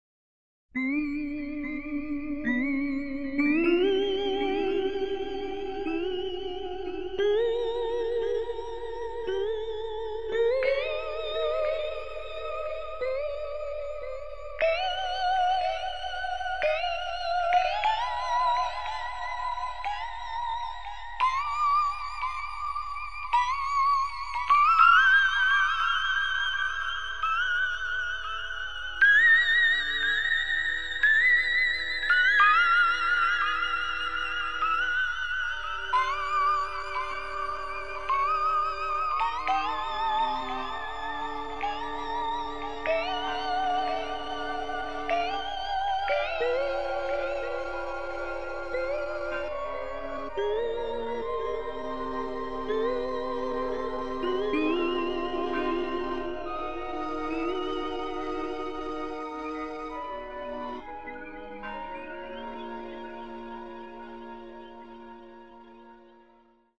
14 сеансов саунд-ингаляции / 14 sound-inhalers
Синтезаторы «Korg» и «Kurzweil»